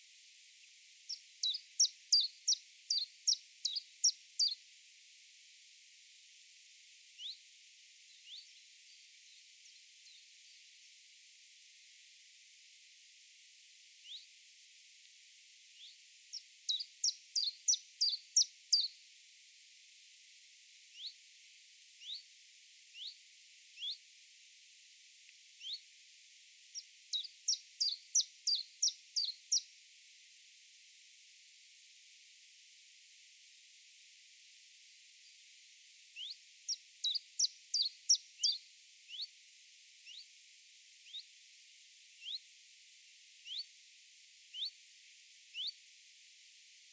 RDV Nature N°50 : le pouillot véloce
🕰 Le métronome de la forêt
Ses deux notes un peu métalliques retentissent en boucle sur le rythme d’un métronome : tsip tsap tsip tsap … ce qui lui a valu le nom anglais : chiffchaff ! 🎶
On le surnomme aussi le compteur d’écus car, en entendant son chant, on imagine des pièces d’argent tombant dans une bourse.